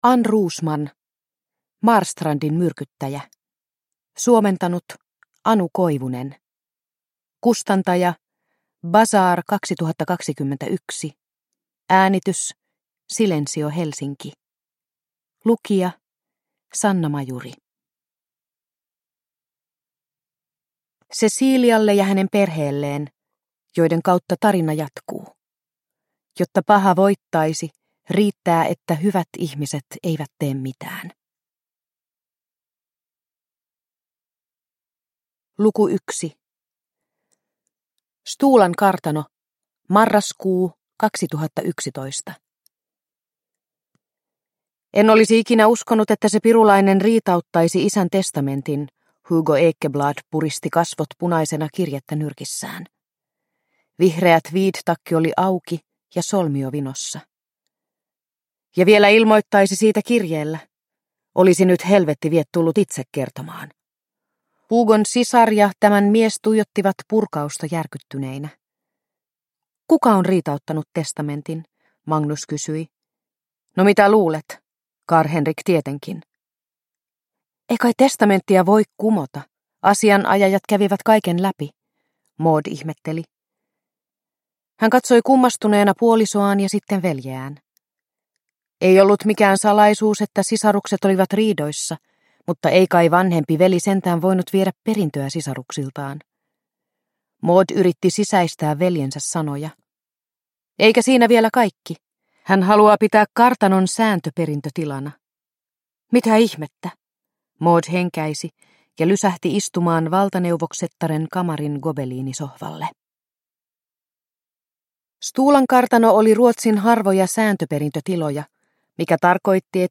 Marstrandin myrkyttäjä – Ljudbok – Laddas ner